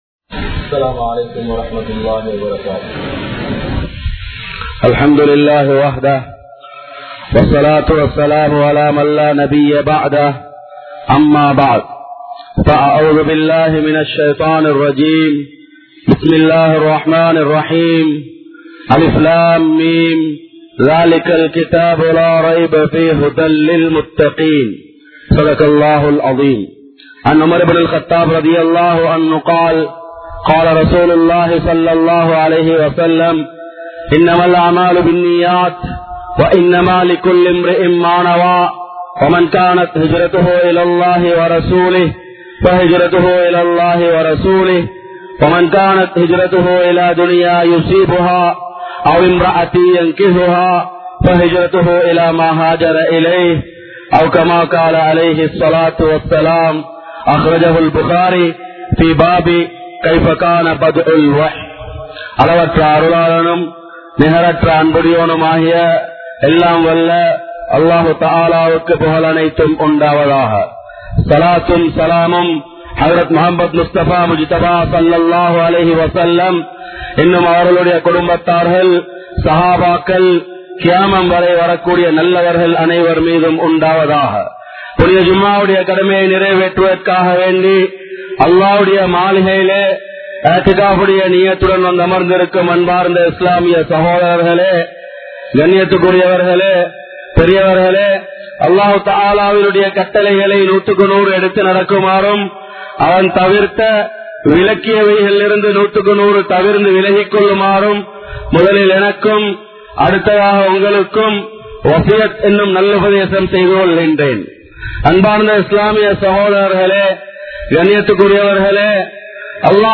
Unmaiyaana Eamaan(உண்மையான ஈமான்) | Audio Bayans | All Ceylon Muslim Youth Community | Addalaichenai